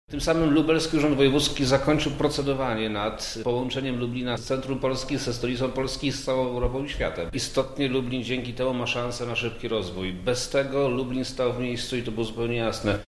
Mówi wojewoda lubelski Przemysław Czarnek